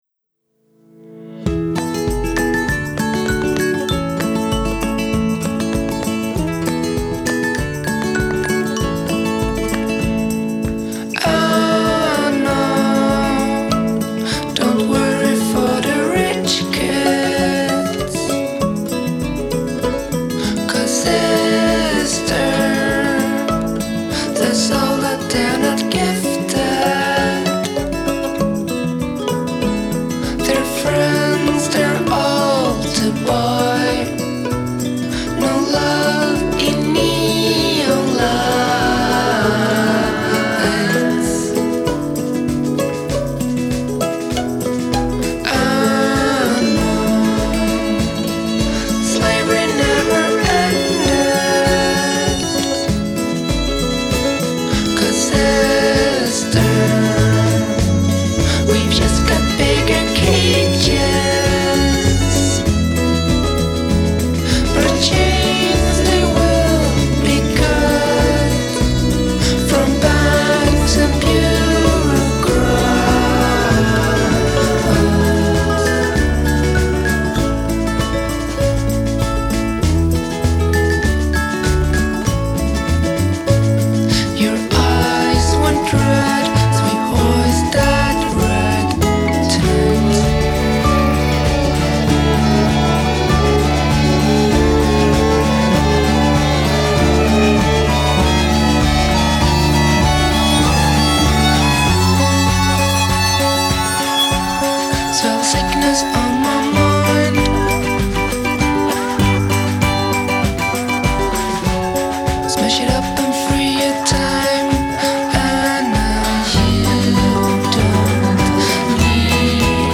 a beautiful slice of pastoral pop